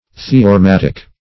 Search Result for " theorematic" : The Collaborative International Dictionary of English v.0.48: Theorematic \The`o*re*mat"ic\, Theorematical \The`o*re*mat"ic*al\, a. [Cf. Gr.